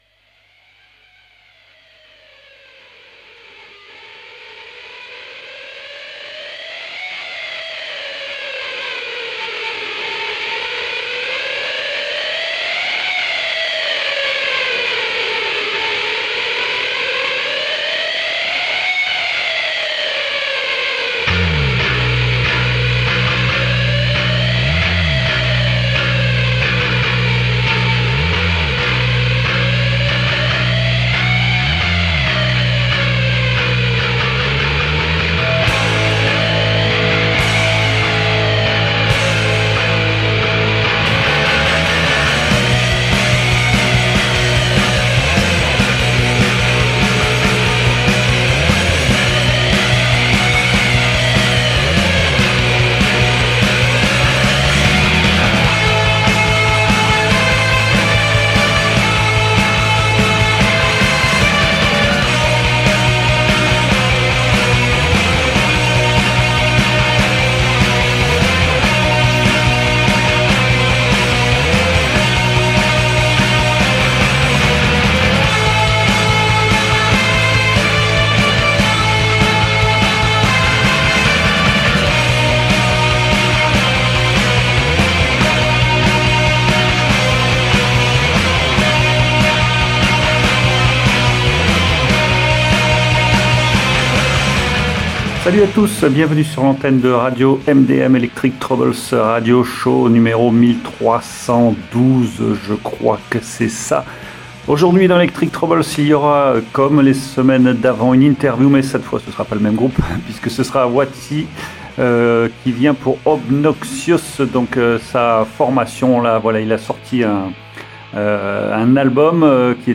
Une chose me marque souvent dans ce style, au-delà du speed, de ce galop effréné, en un minimum de temps et d’espace,